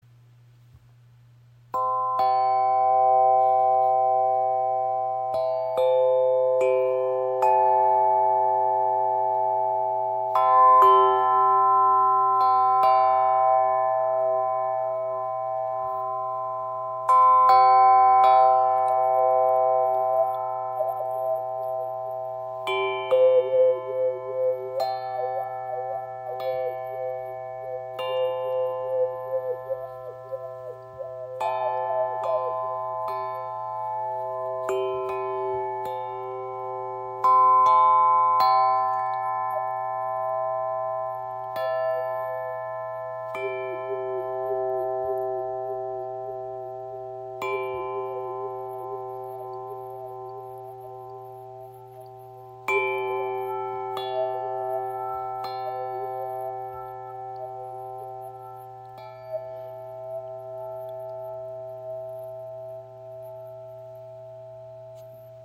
Wayunki in G-Dur & C-Dur – Sanfte Klänge in 432 Hz
Gestimmt auf A – C – G – E – C – G in 432 Hz, öffnet sie Herz- und Kehlchakra und erzeugt ein harmonisches, ausgewogenes Klangbild.